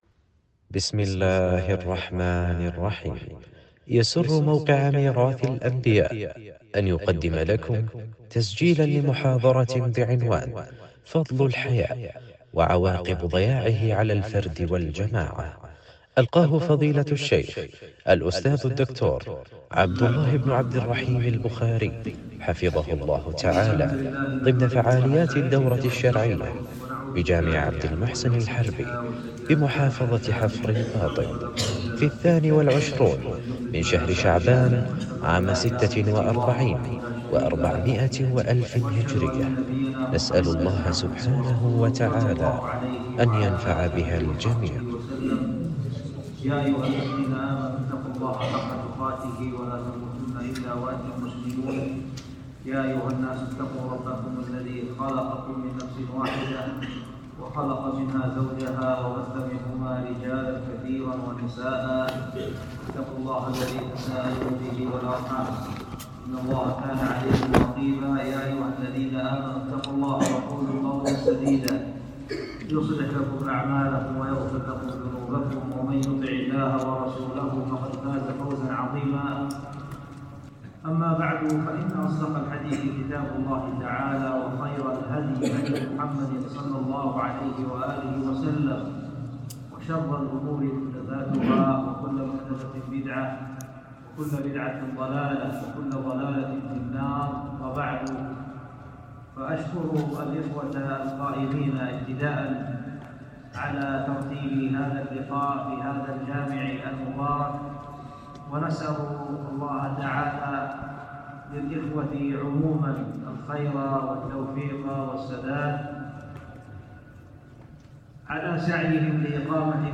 محاضرة
محاضرة-فضل-الحياء.mp3